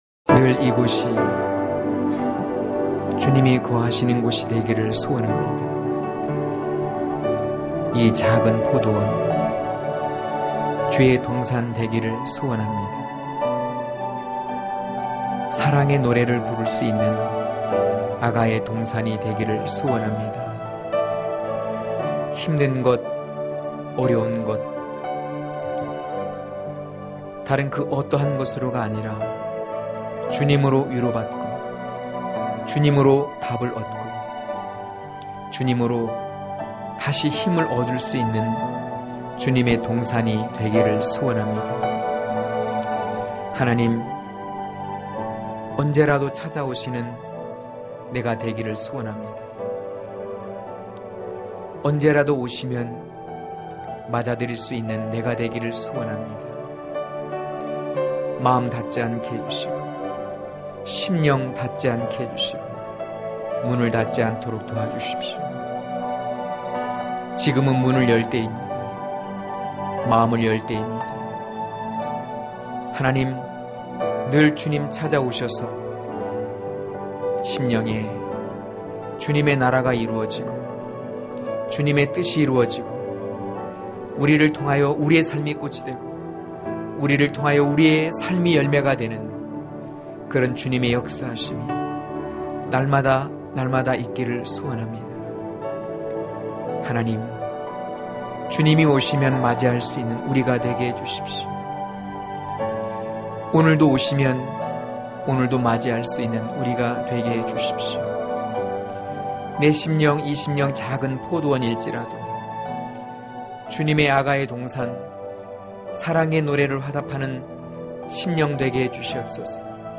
강해설교 - 02.어디에서나 주님께 보이기(아1장5-8절)